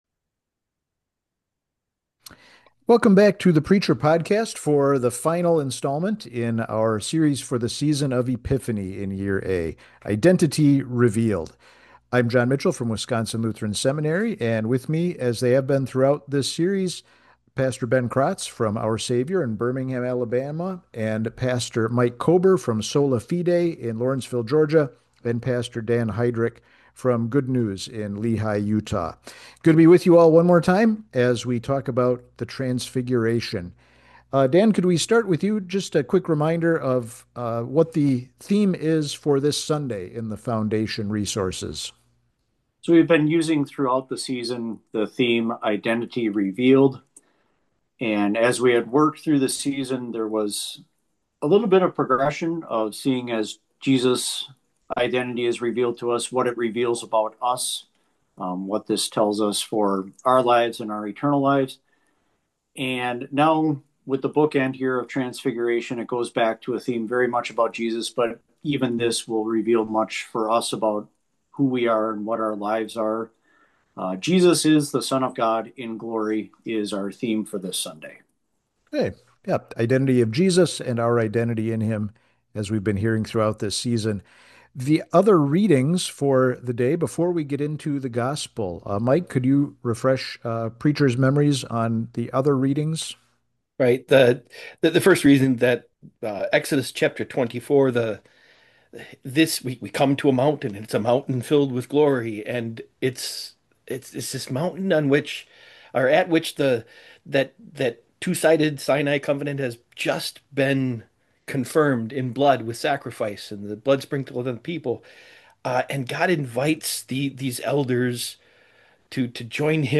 Listen to multiple pastors discu